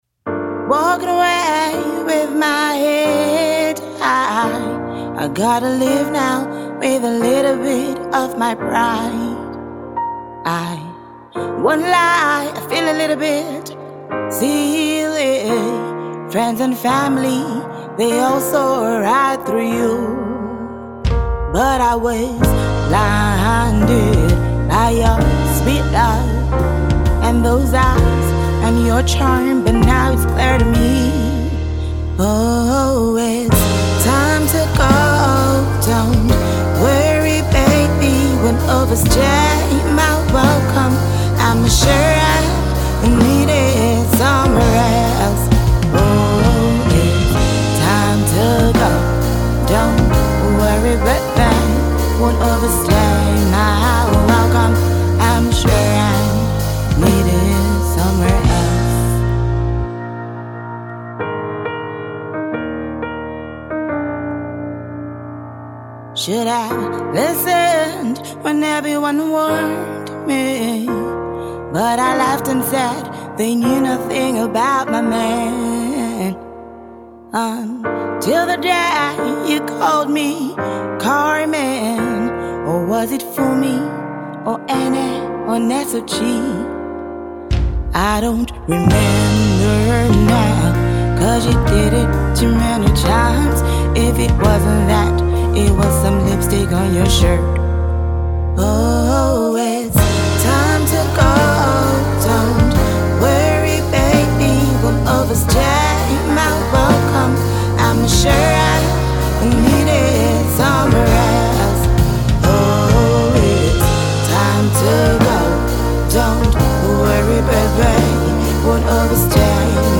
Jazz and Soul songstress